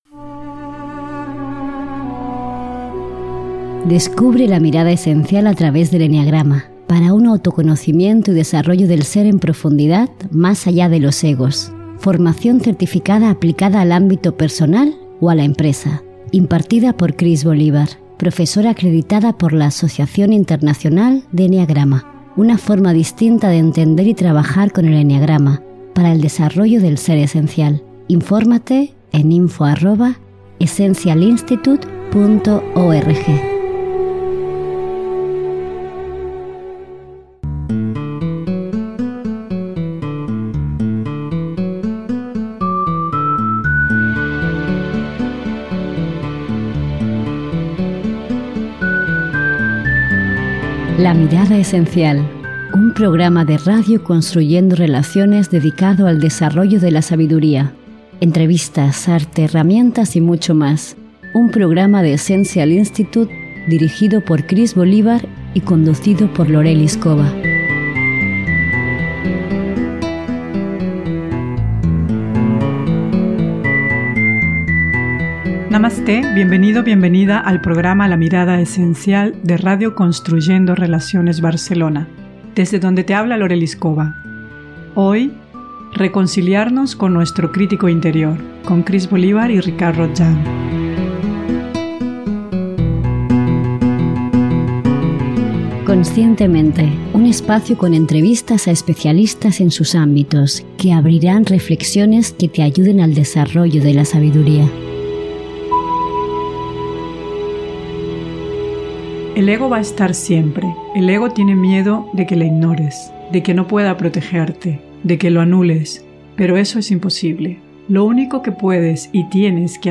RADIO «LA MIRADA ESENCIAL» – RECONCILIARNOS CON NUESTRO CRÍTICO INTERIOR – 7-8-19